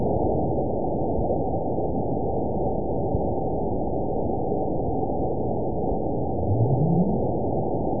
event 912227 date 03/21/22 time 19:01:30 GMT (3 years, 1 month ago) score 9.70 location TSS-AB02 detected by nrw target species NRW annotations +NRW Spectrogram: Frequency (kHz) vs. Time (s) audio not available .wav